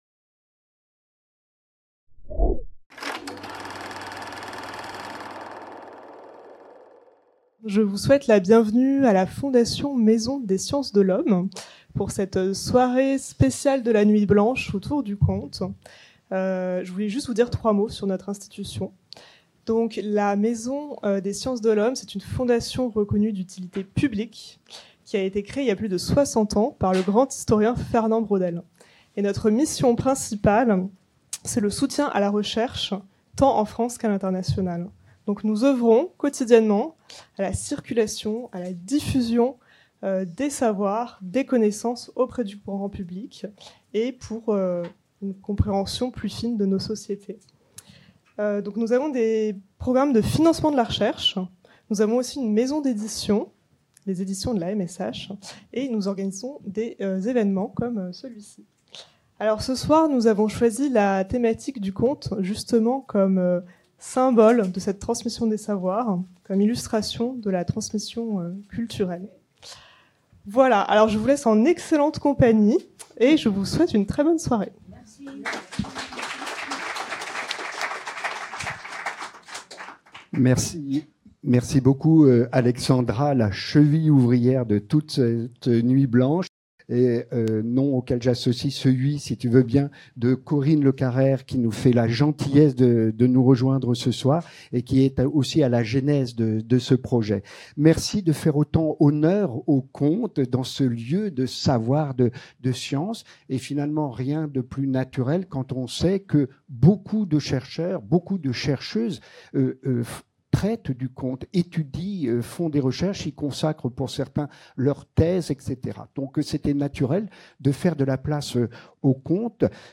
Rencontre à la FMSH, pour explorer le pouvoir des contes, à l'occasion de la nuit blanche parisienne, qui s'est tenue le 7 juin 2025